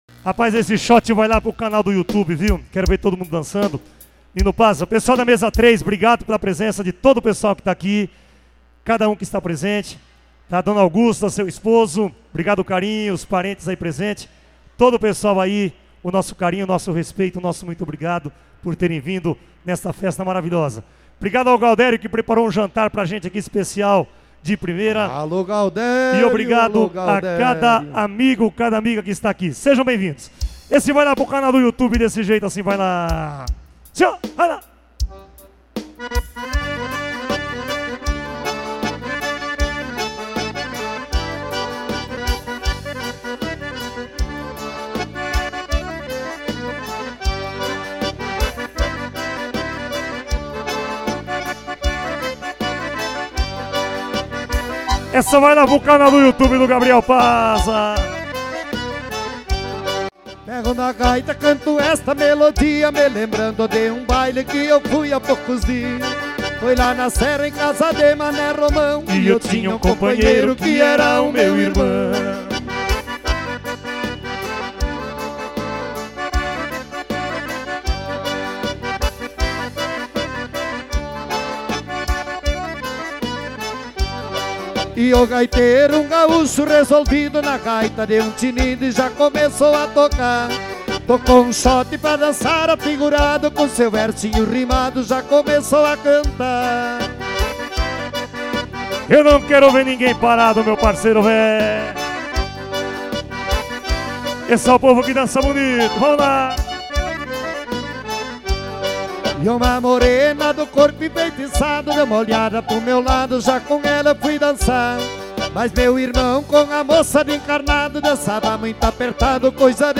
XOTE